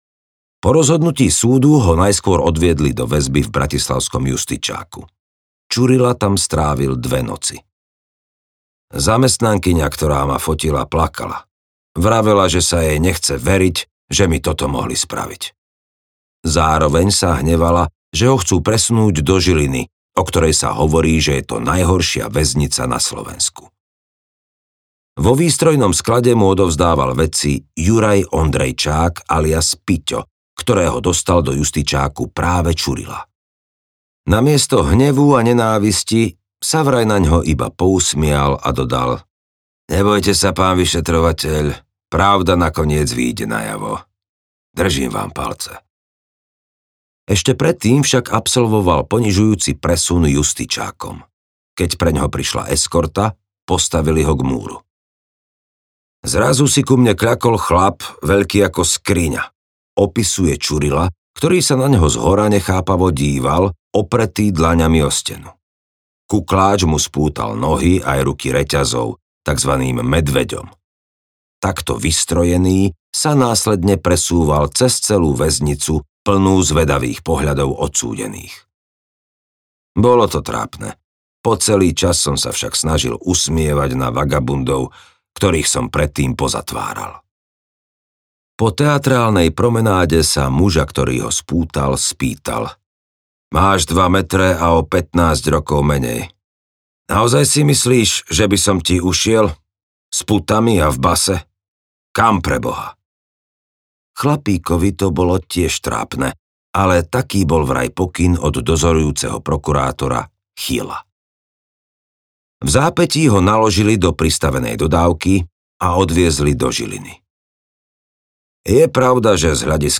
Vypočujte si ukážku audioknihy